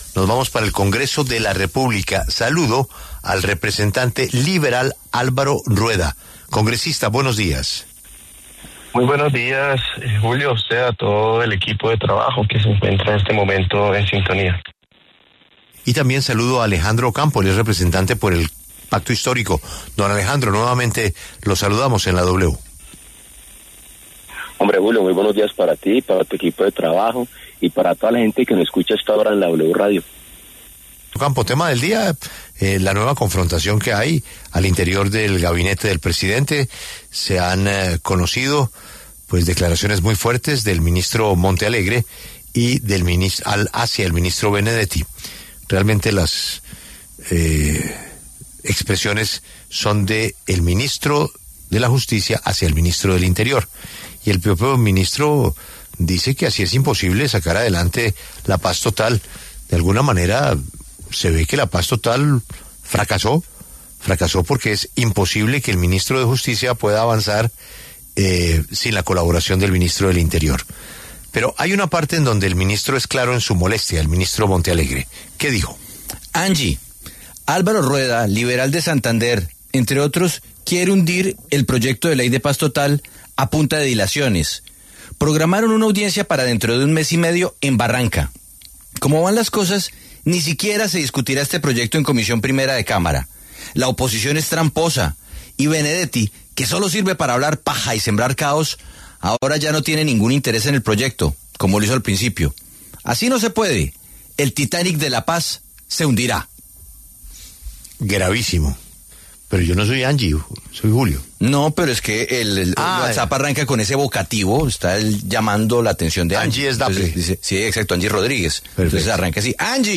¿Benedetti "no tiene interés" en mover los proyectos del Gobierno, como dice Montealegre?: Debate